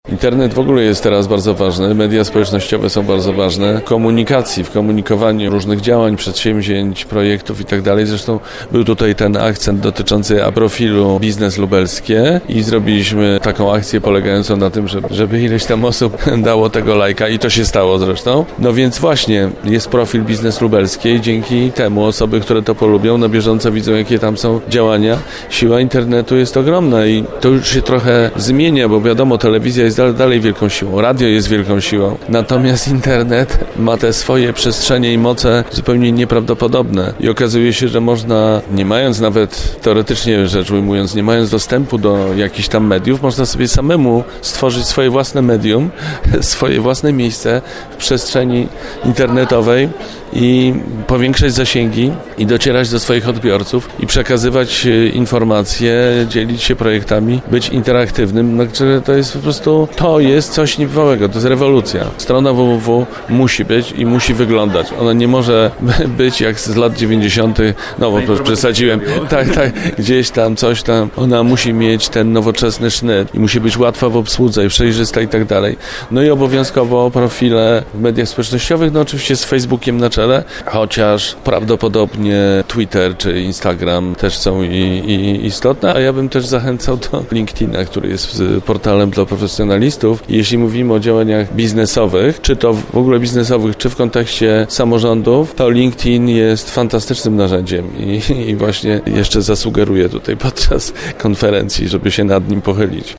O tym, jak „złapać w sieć” potencjalnego inwestora i dlaczego miasto, gmina czy powiat powinny być dla inwestorów atrakcyjne rozmawiali w środę  (07.03) w Lublinie samorządowcy z całego naszego województwa.
Moderatorem wydarzenia był dziennikarz telewizyjny Maciej Orłoś, który mówił jakie znaczenie w dynamicznie zmieniającej się rzeczywistości mają internet, telewizja i media społecznościowe.